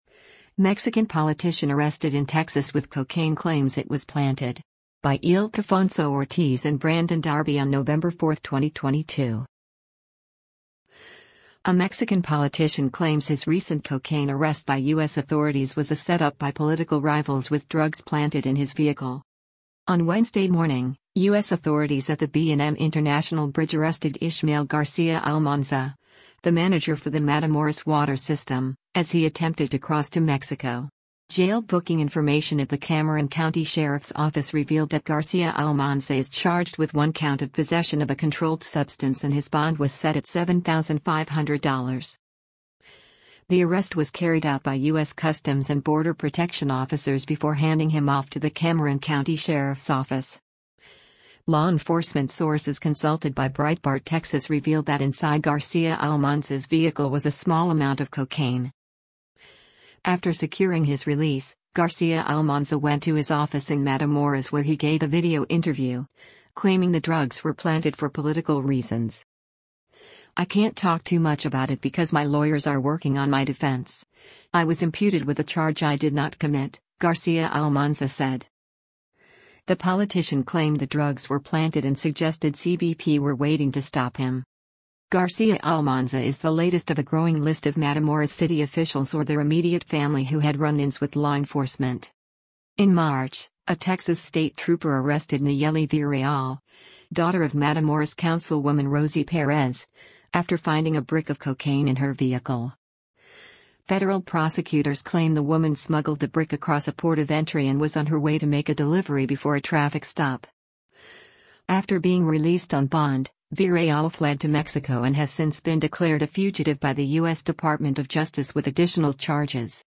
After securing his release, Garcia Almanza went to his office in Matamoros where he gave a video interview, claiming the drugs were planted for political reasons.